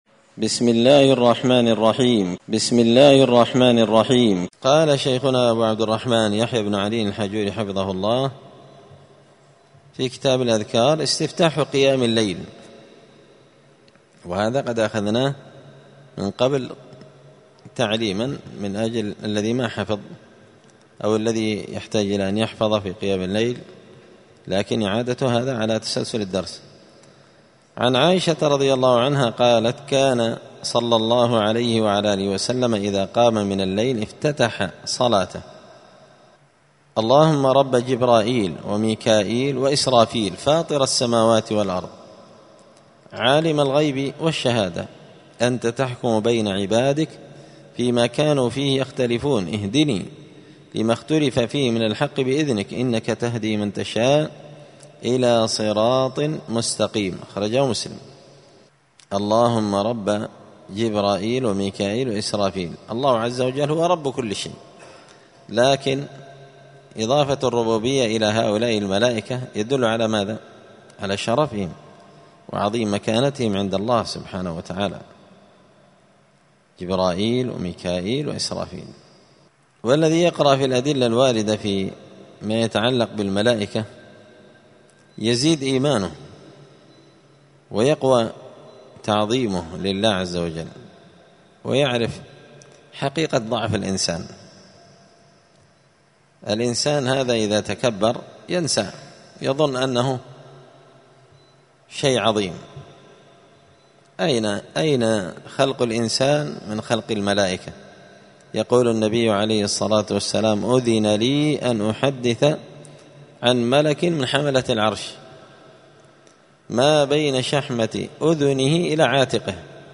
*{الدرس الخامس والعشرون (25) أذكار الصلاة استفتاح قيام الليل}*
دار الحديث السلفية بمسجد الفرقان بقشن المهرة اليمن